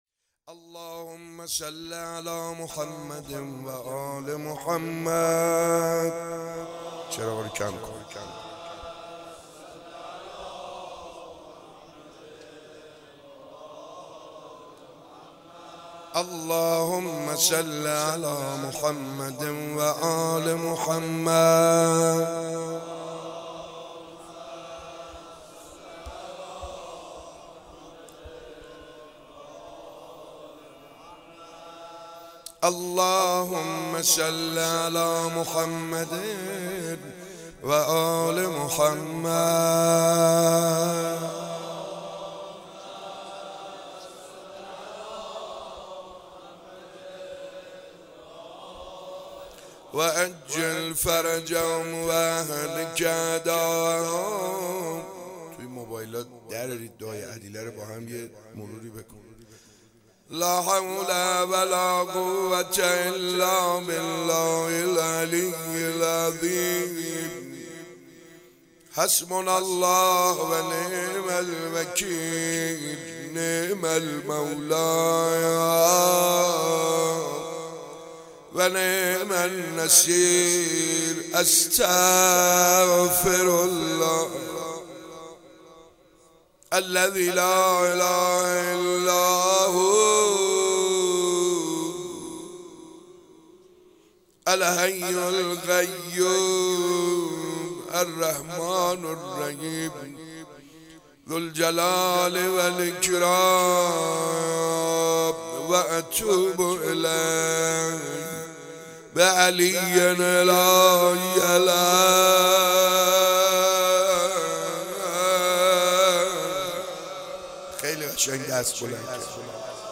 جلسه هفتگی 5 مرداد 96 - مناجات